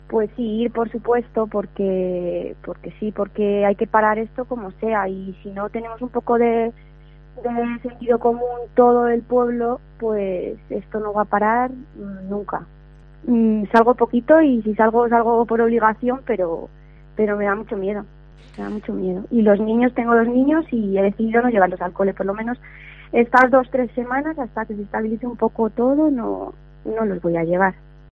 Vecina 1 Navarredonda de Gredos